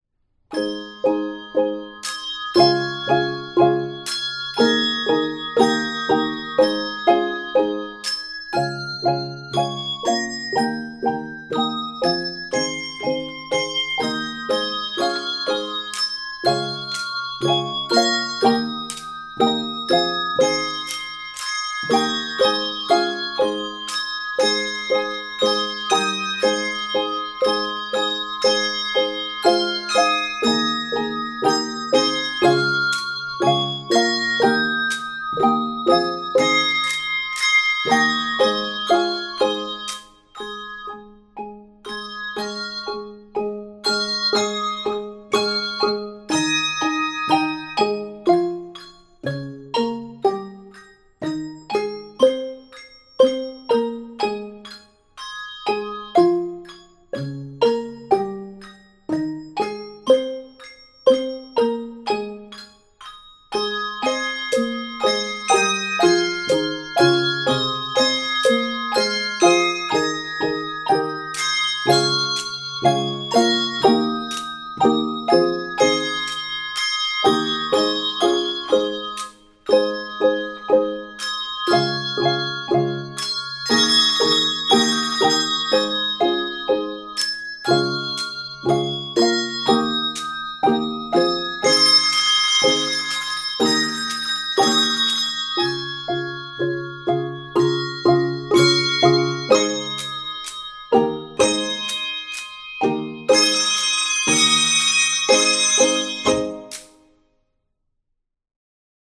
happy and uplifting arrangement
Key of Ab Major.